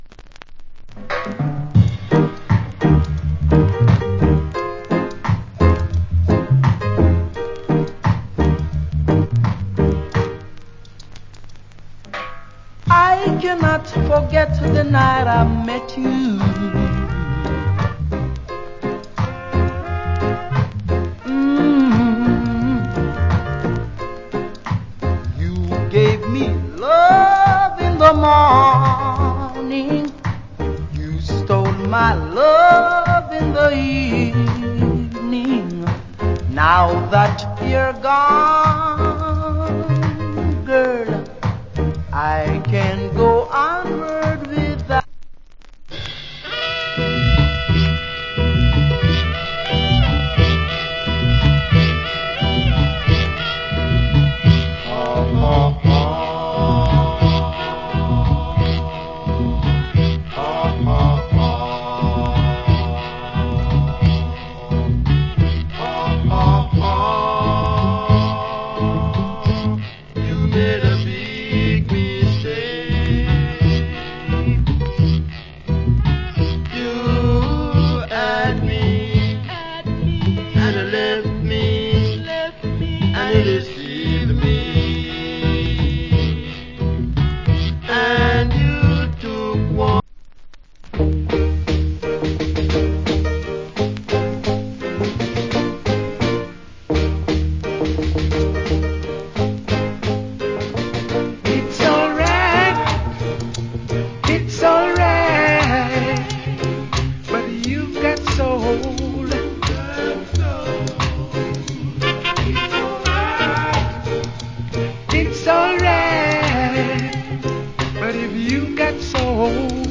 Nice Rock Steady.